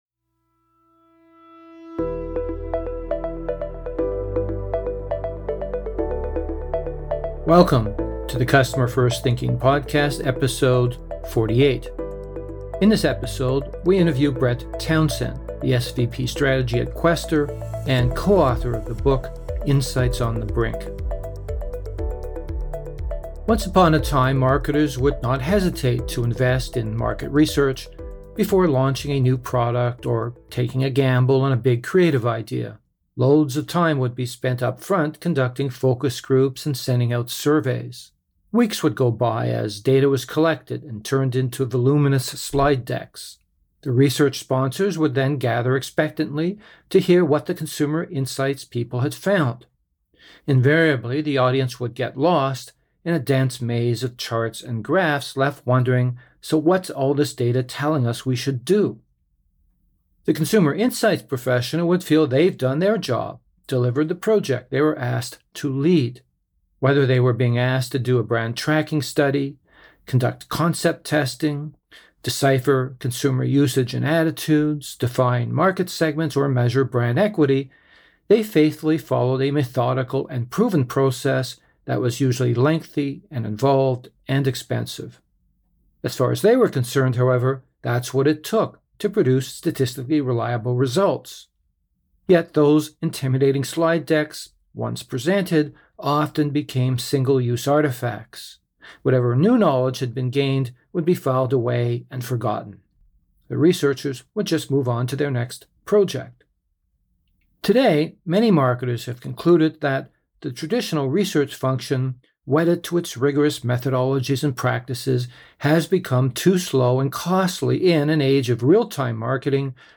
Saving Market Research: An Interview